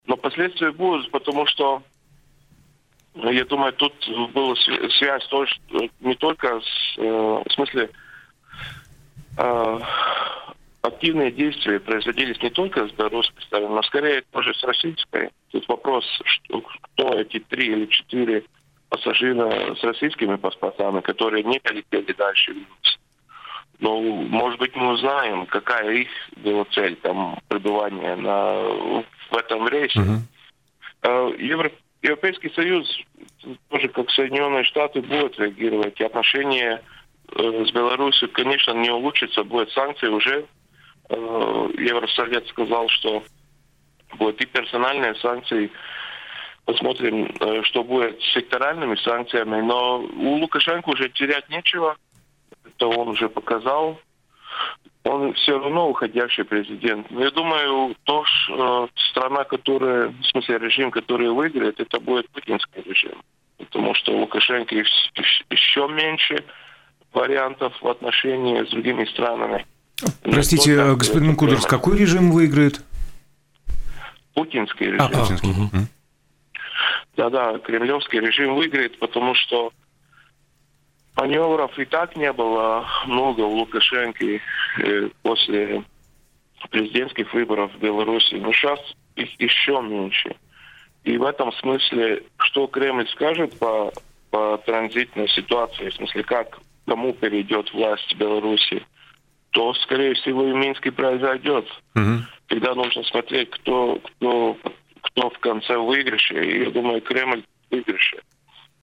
Такое мнение в эфире радио Baltkom высказал политолог